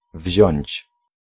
Ääntäminen
France: IPA: [ʁǝ.pʁɑ̃dʁ]